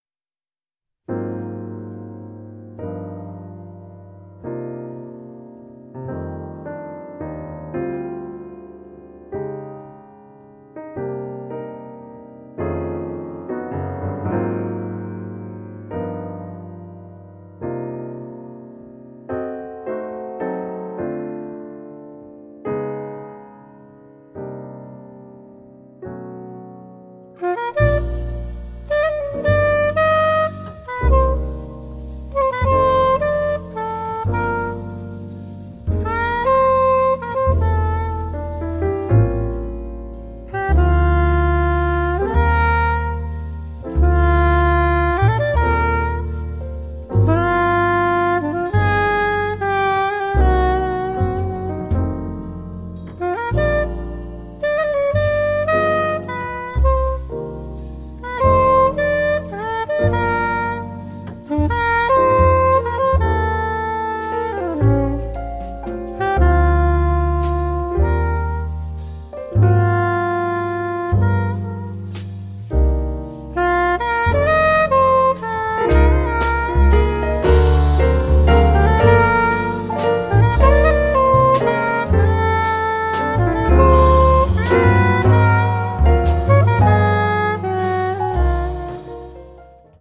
sax soprano e tenore
pianoforte
contrabbasso
batteria